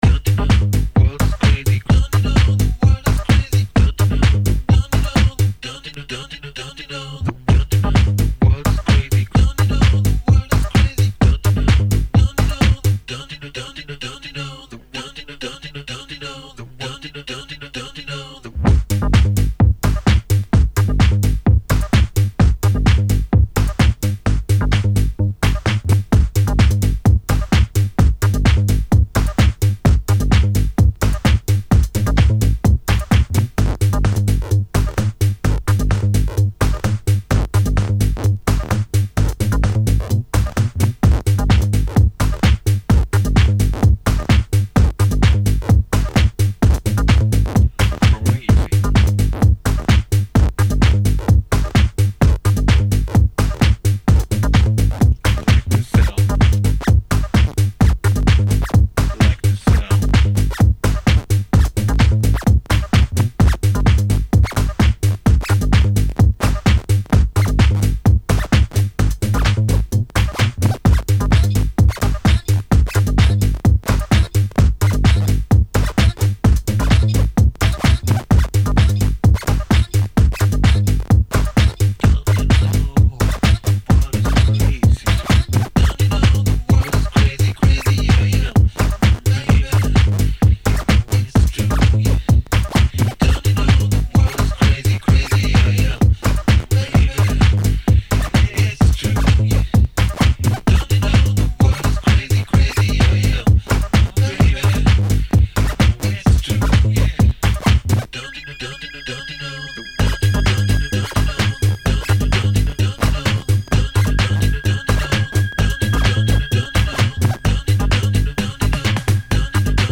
Muzyka/Music: House/Disco/Minimal Techno/Funk
Exclusive DJ mix session.